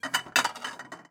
Babushka / audio / sfx / Kitchen / SFX_Plates_01.wav
SFX_Plates_01.wav